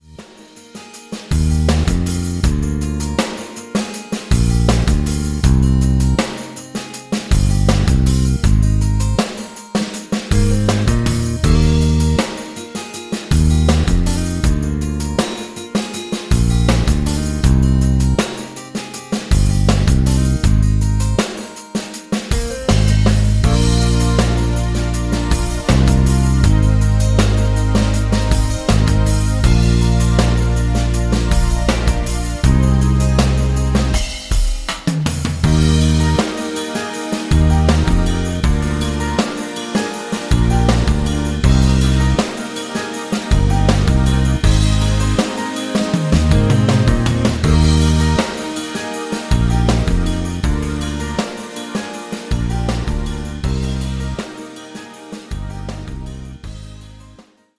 Karaoke, Instrumental